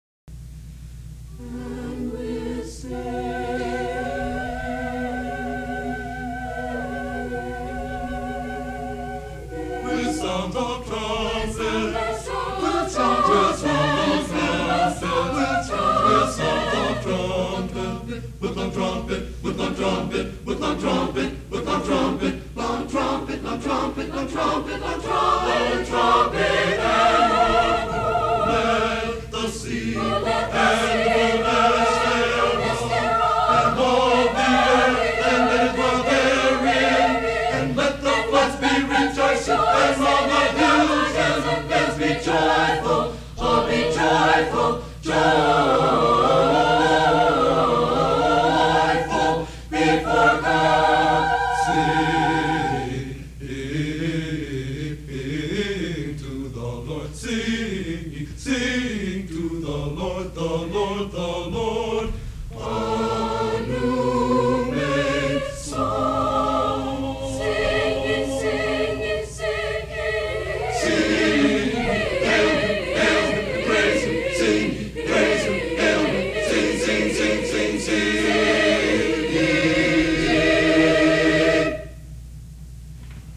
Highland Park, MI, High School Concert Choirs, 1954-1969
CD for the 1966 and 1967 Spring Concerts